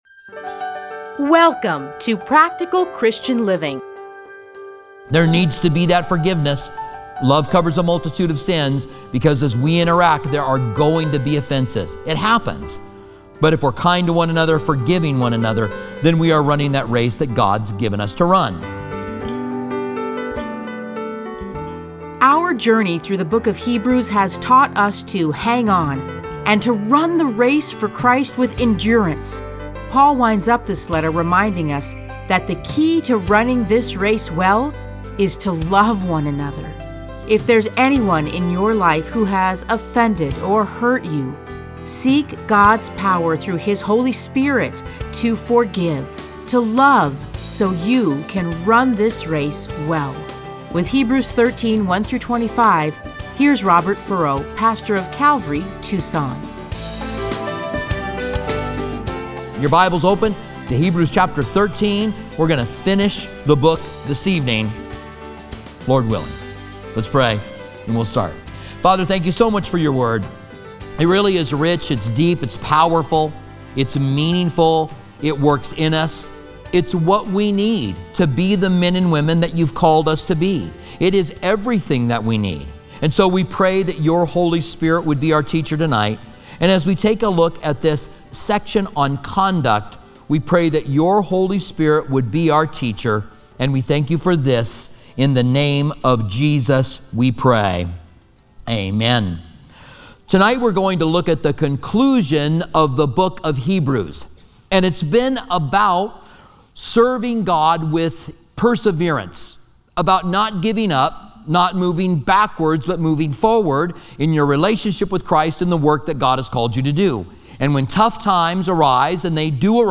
Listen to a teaching from Hebrews 13:1-25.